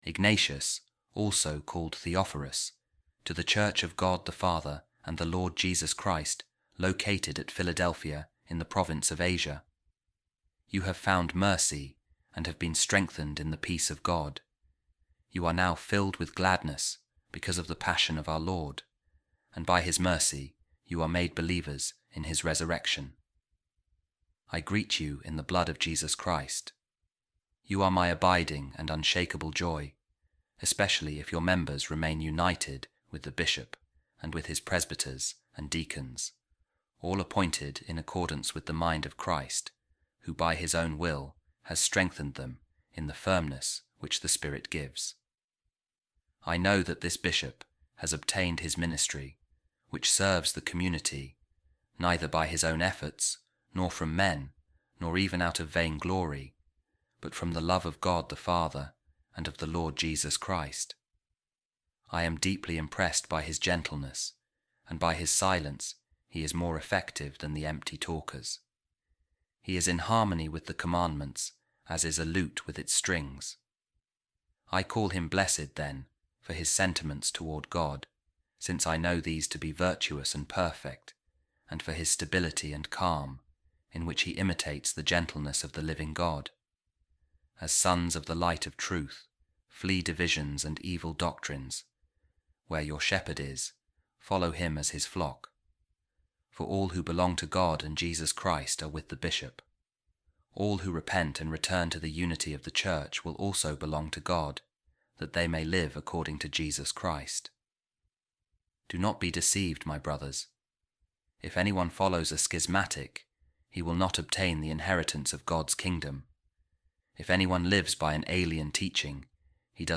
A Reading From The Letter Of Saint Ignatius Of Antioch To The Philadelphians | One Bishop With The Presbyters And Deacons